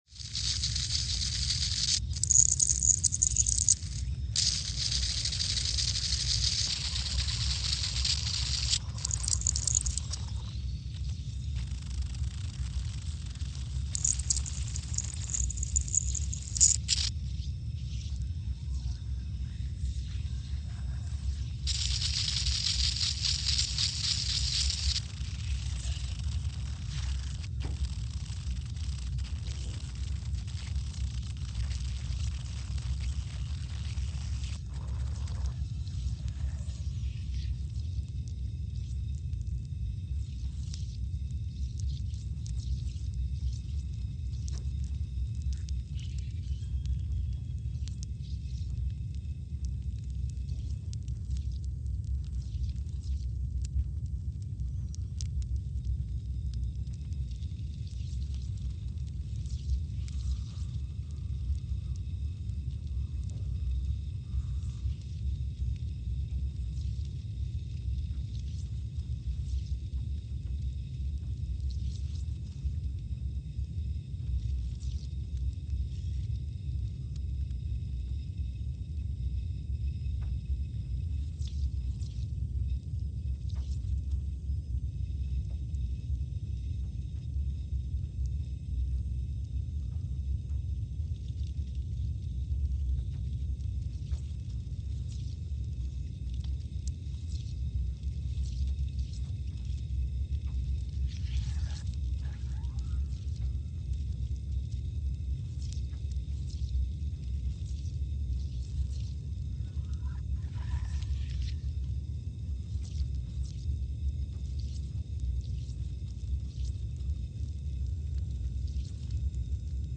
Scott Base, Antarctica (seismic) archived on December 8, 2022
Sensor : CMG3-T
Speedup : ×500 (transposed up about 9 octaves)
Loop duration (audio) : 05:45 (stereo)
SoX post-processing : highpass -2 90 highpass -2 90